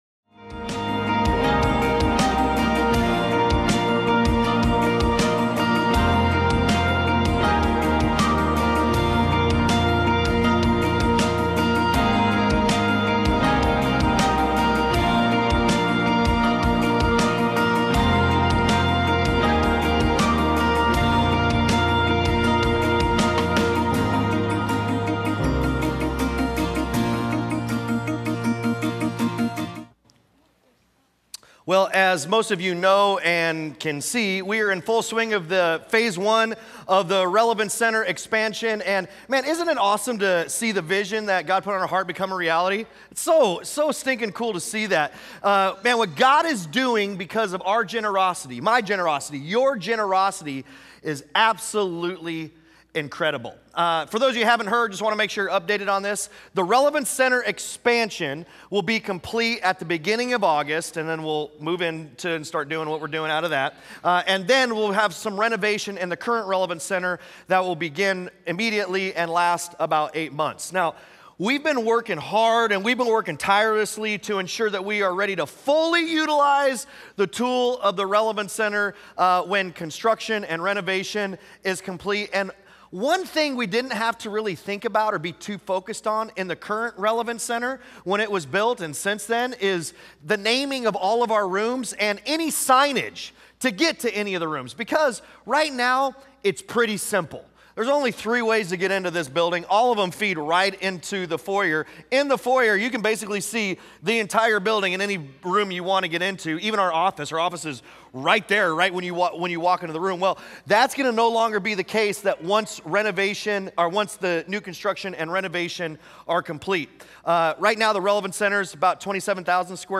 Sunday Sermons Miracles?, Week 2: "What’s the Purpose?"